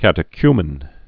(kătĭ-kymən)